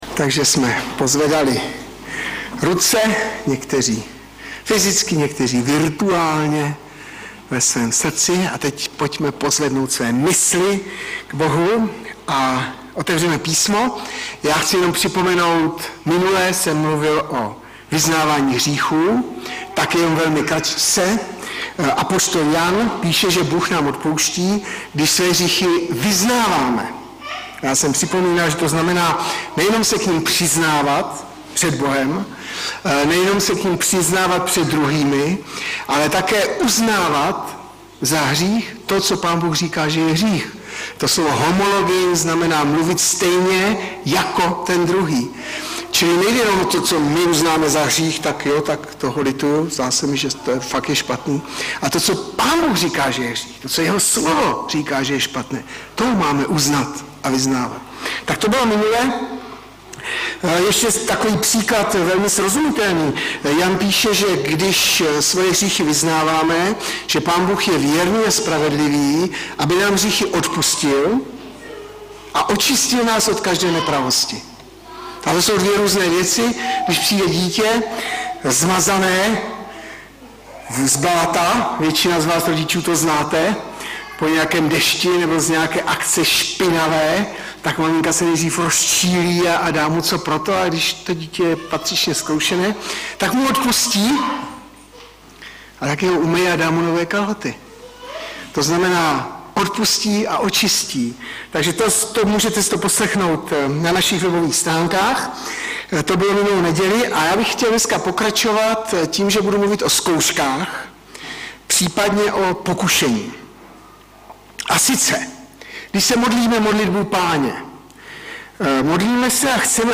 Webové stránky Sboru Bratrské jednoty v Litoměřicích.
Audiozáznam kázání